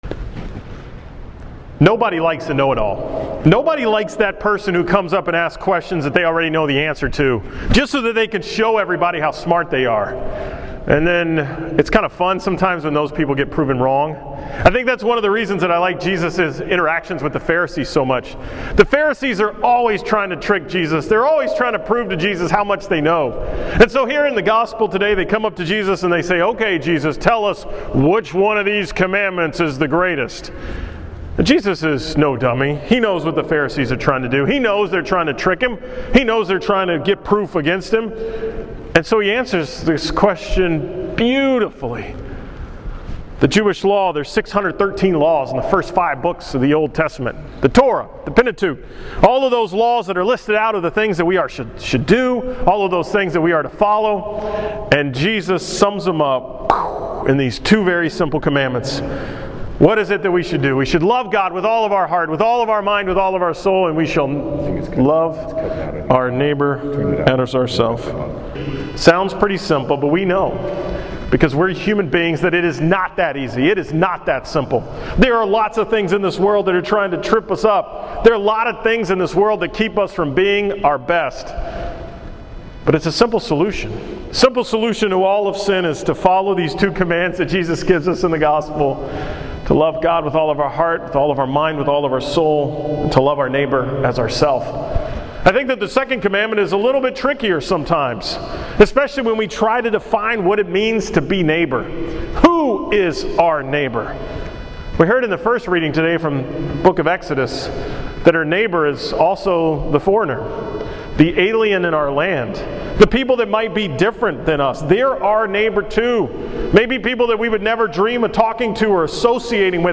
From the 5 pm Mass on October 25th at St. Jerome
Category: 2014 Homilies